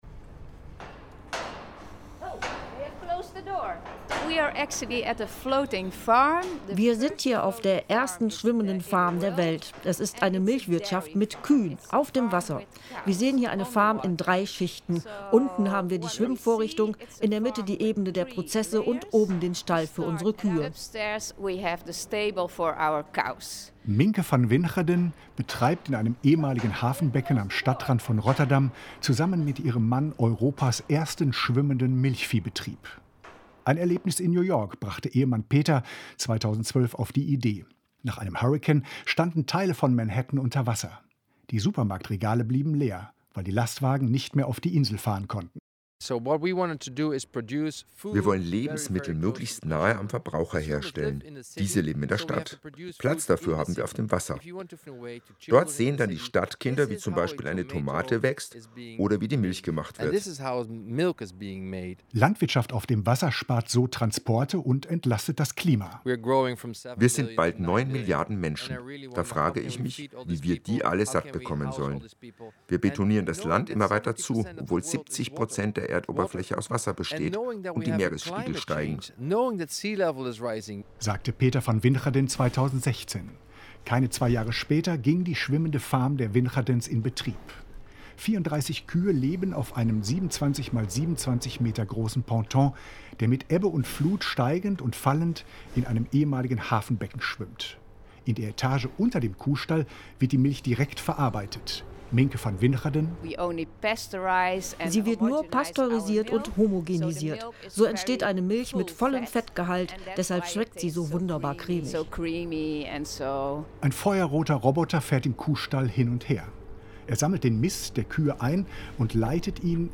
meine Reportage von Europas erstem Kuhstall auf dem Wasser im Deutschlandfunk, Frühjahr 2020: